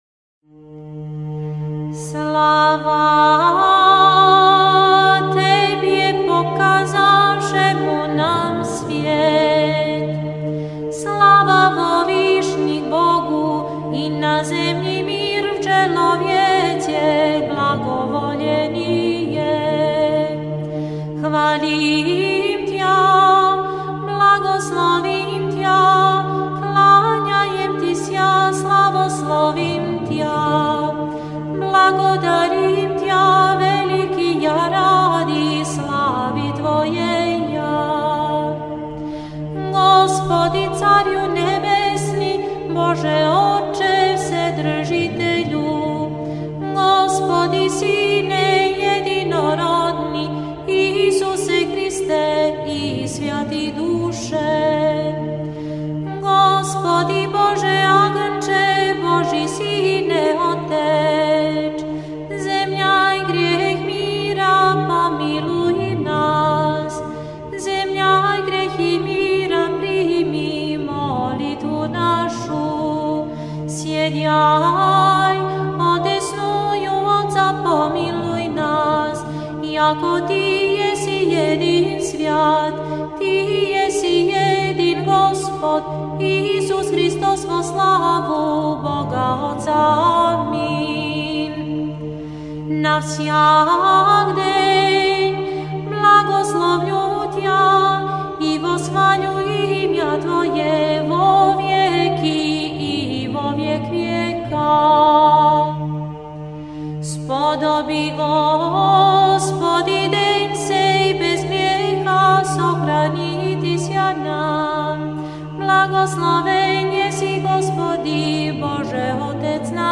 Звонят ко всенощной..
( Очень чистый звон, малиновый у нашей колокольни...)